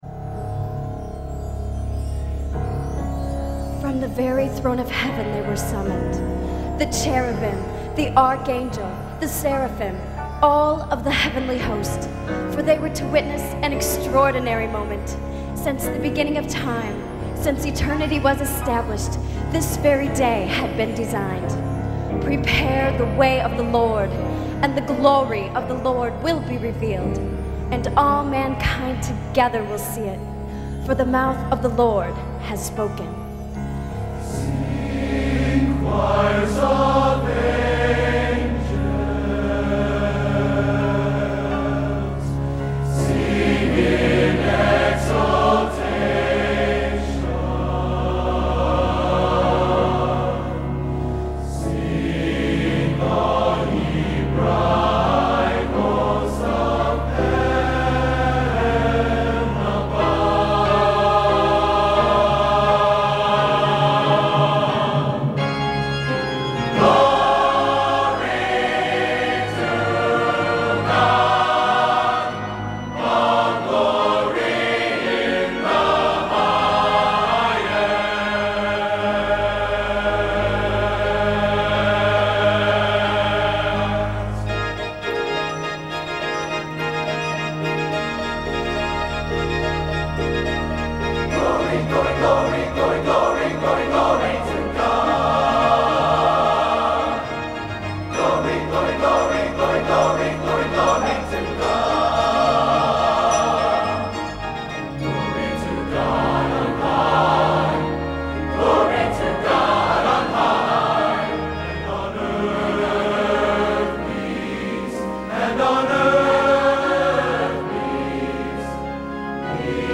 Collection: Christmas Show 1994
Location: West Lafayette, Indiana
Genre: | Type: Christmas Show |